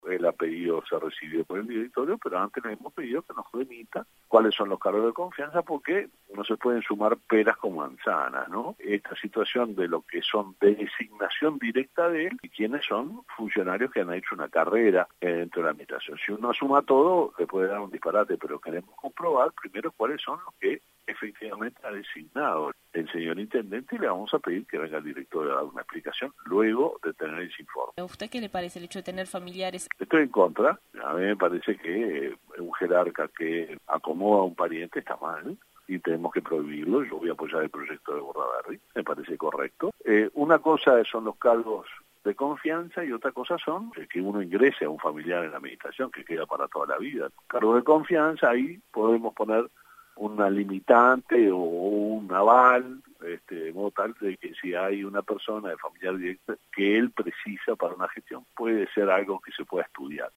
El presidente del directorio del Partido Nacional, Luis Alberto Heber, habló con Informativo Universal y expresó que se decidió pedir un informe al intendente de Artigas sobre los cargos que ocupan sus familiares en la comuna.